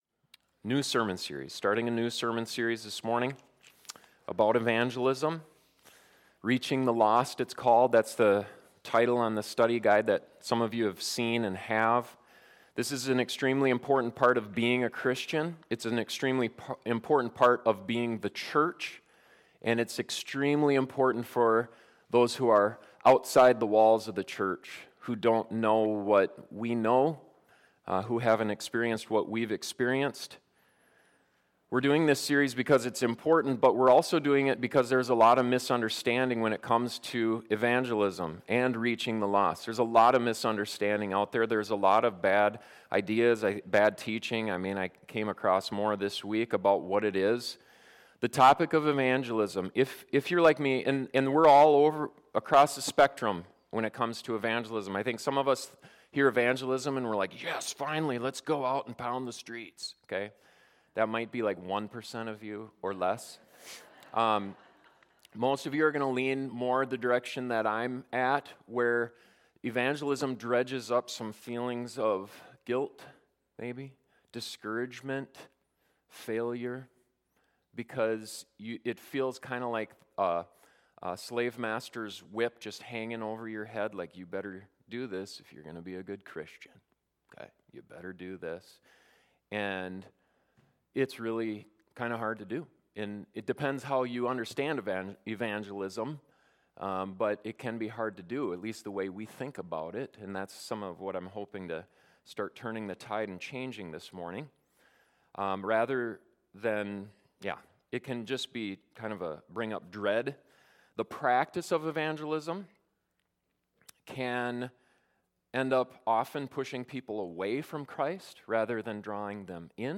This sermon seeks to unfold the heart of evangelism in Scripture to revive our hope and joy.